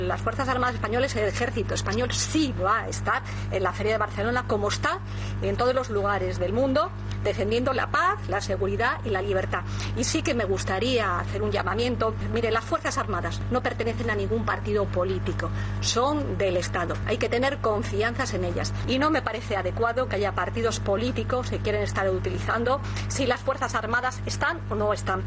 "El Ejército español va a estar presente en la Feria de Barcelona", recalcó Robles en una rueda de prensa en Bruselas, donde participa en un Consejo de ministros de la Unión Europea, después de que el Ministerio que dirige informara de que no iba a estar presente en la mencionada cita.